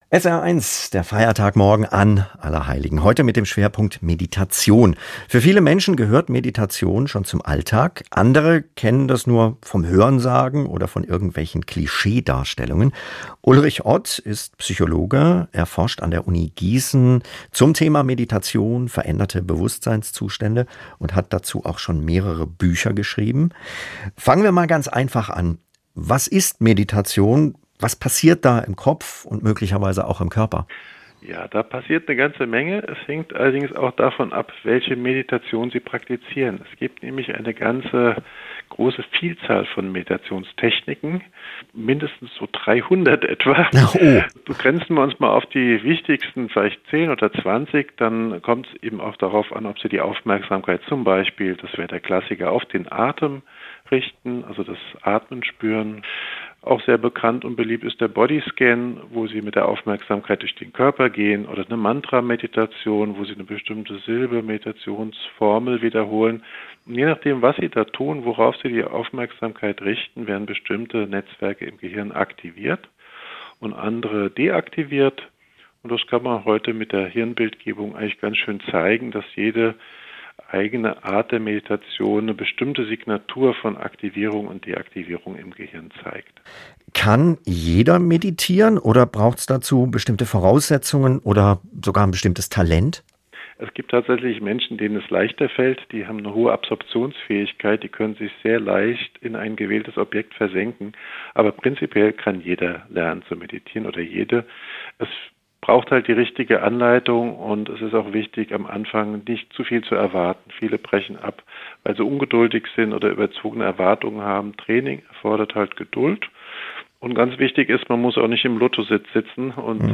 SWR1-Gespräch